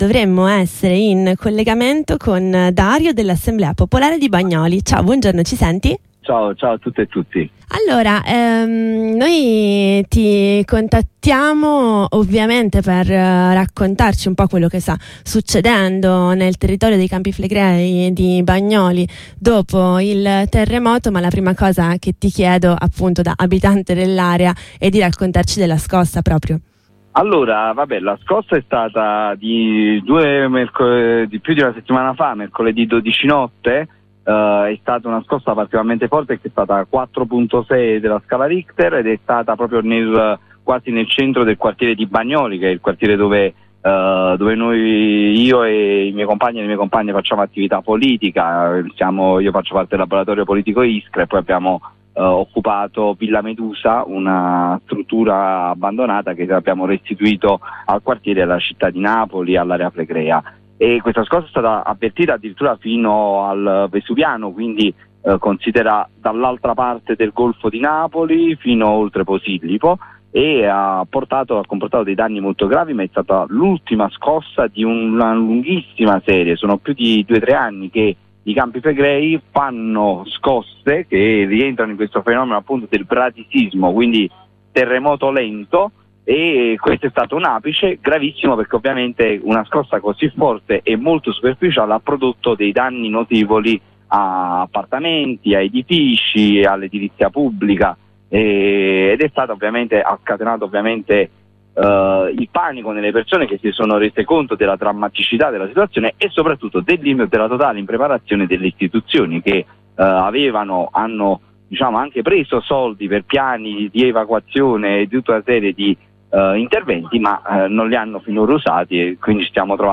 Ascolta e scarica la diretta.